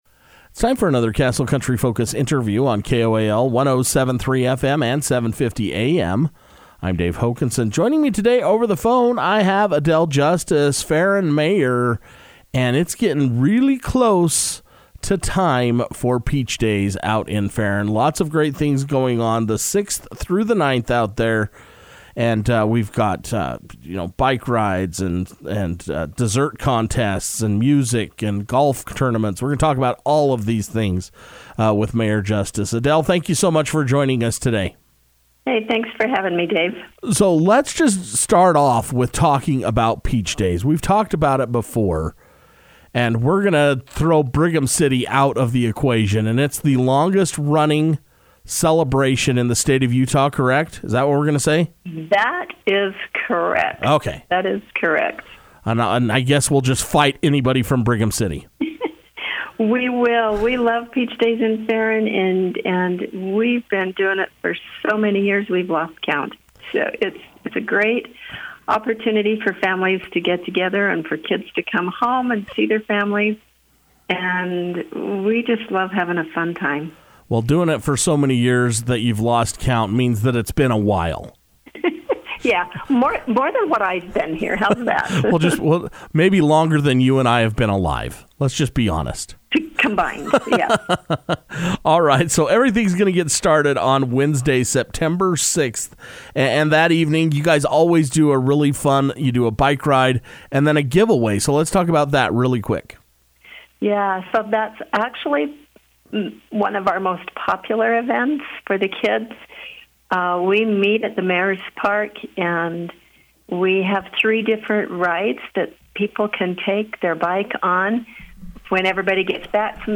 It’s time for Ferron City’s annual town celebration, Peach Days is set to take place the week of September 6. Mayor Adele Justice took time to speak over the telephone with Castle Country Radio to discuss all the details of the celebration.